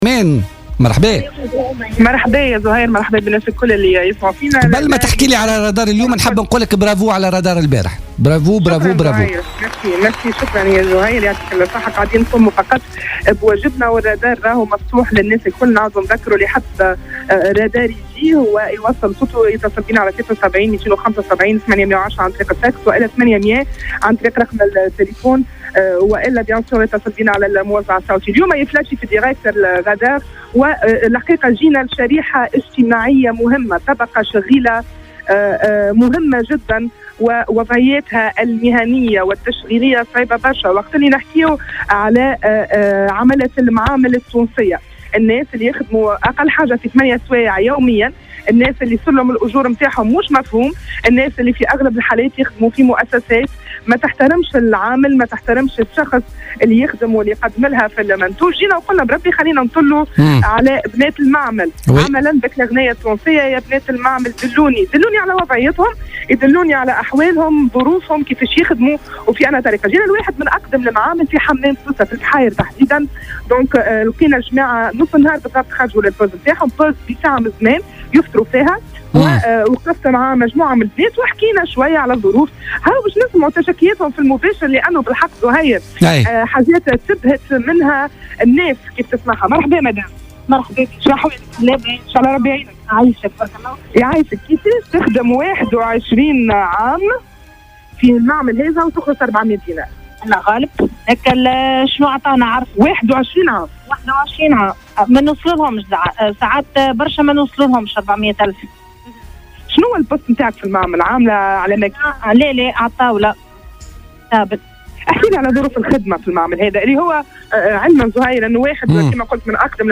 تنقل الرادار اليوم الثلاثاء 8 نوفمبر 2016 إلى مصنع النسيج في منطقة "البحاير" بحمام سوسة لرصد المشاكل التي تعترض العاملات هناك.